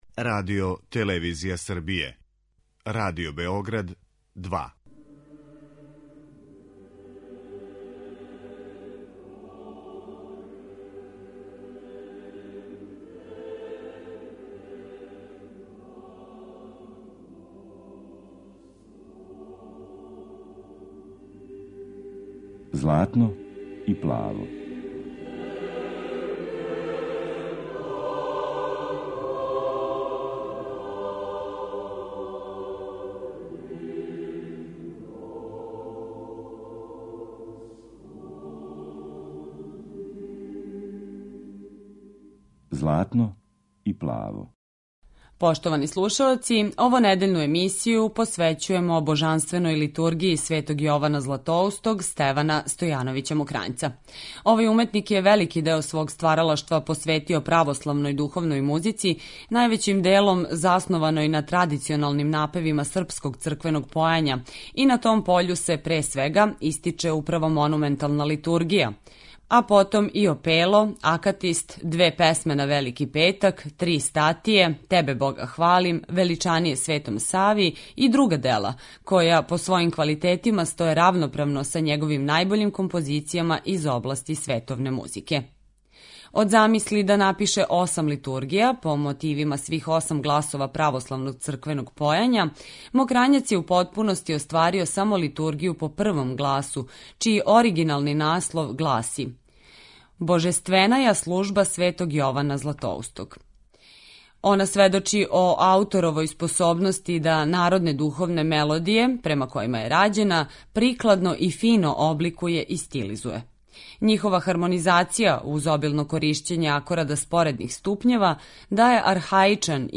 Емисија православне духовне музике